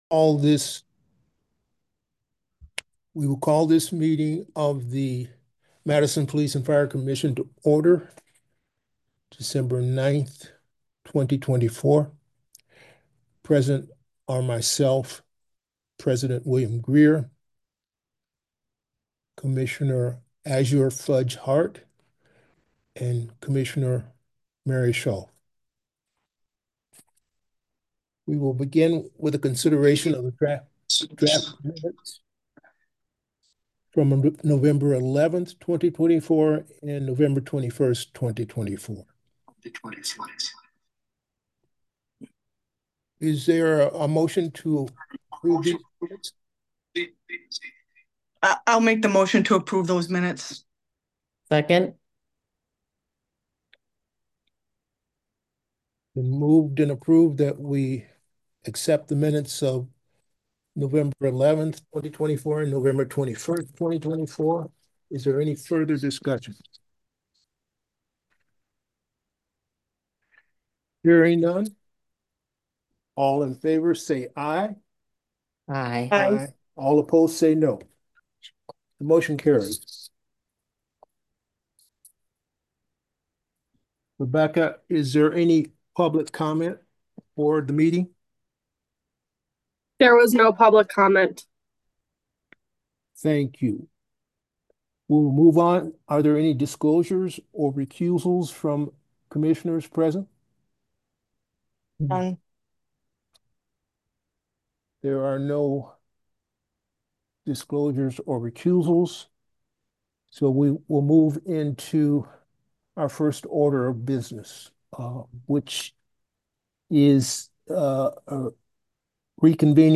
This is a podcast of Additional Meetings not normally covered by Madison City Channel.